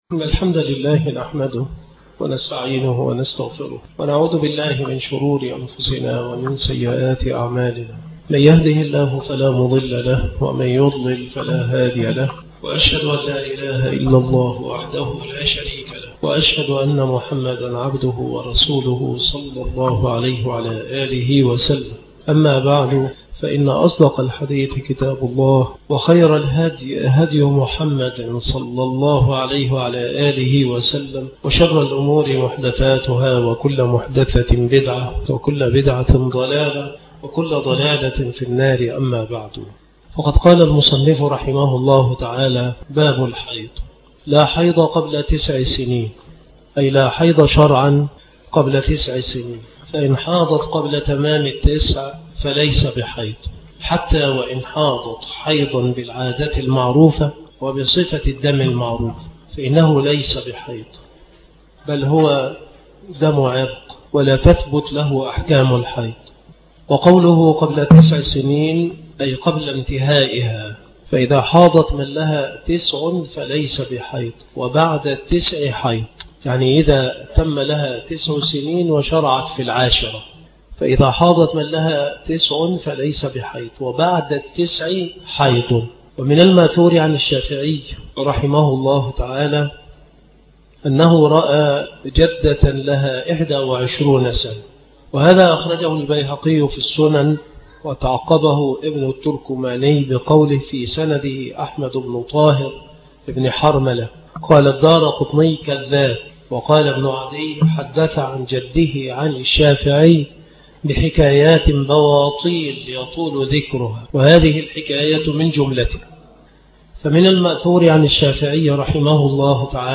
محاضرات وكلمات